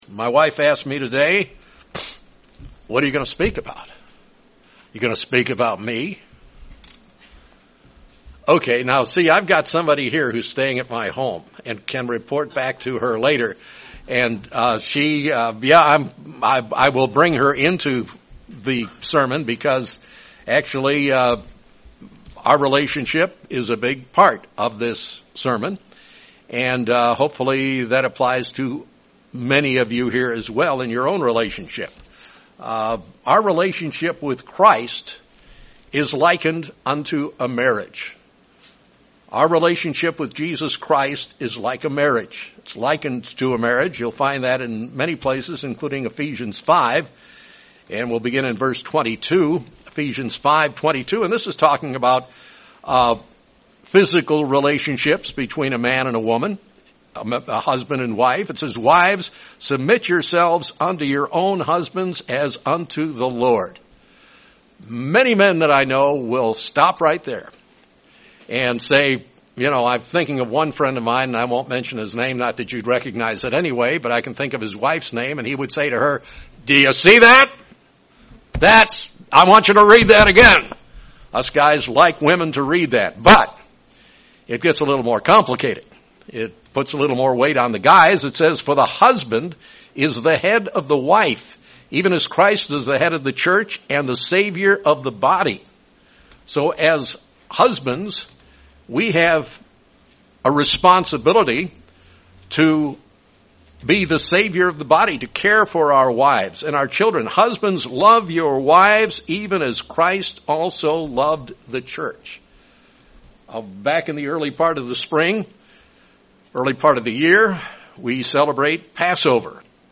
In this sermon, the speaker looks into three parallels between our physical marriages and the marriage of the church with Jesus Christ.